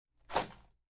描述：介绍拳1
Tag: Vermona 冲床 介绍 1